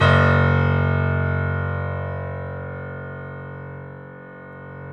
Index of /90_sSampleCDs/E-MU Producer Series Vol. 5 – 3-D Audio Collection/3D Pianos/BoesPlayHardVF04